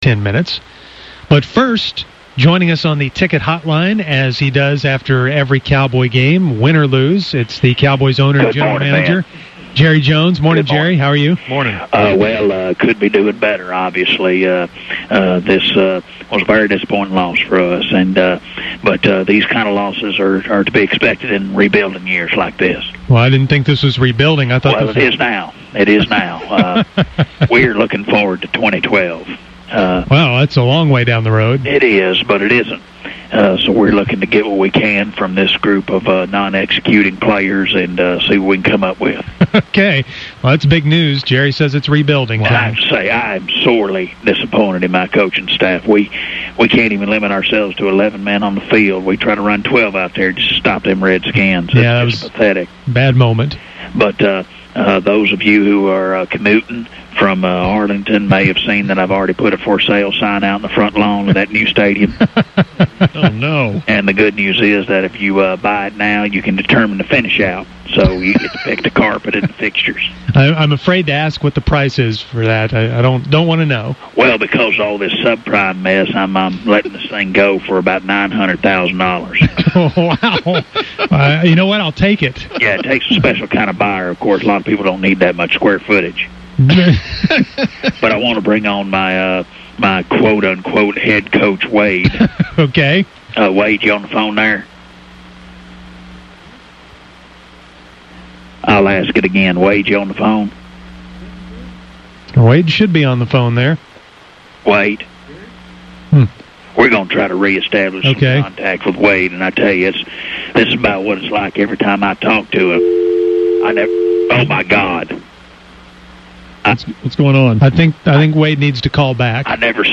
Lucky for us, we can follow it up the next day with a phone call from the Fake Coaches to talk about the game.